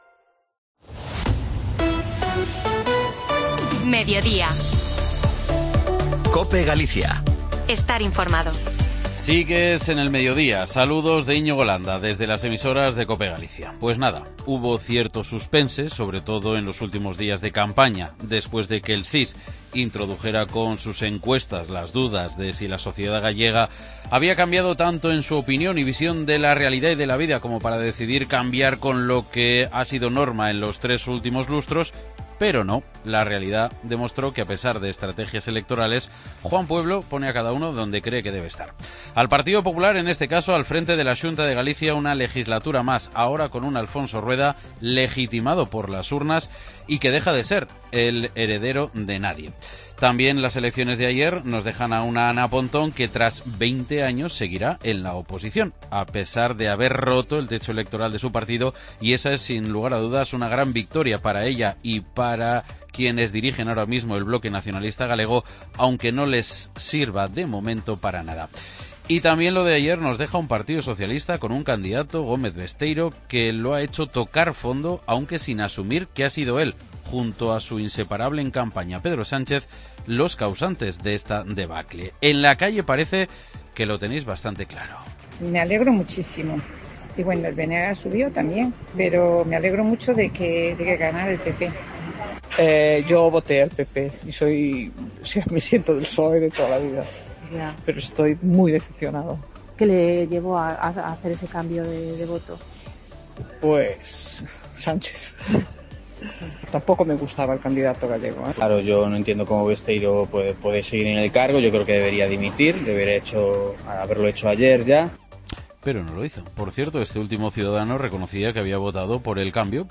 AUDIO: Analizamos los resultados de las elecciones del 18F en Galicia y esuchamos las reacciones en la calle a la victoria del Partido Popular y la...